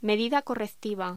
Locución: Medida correctiva
voz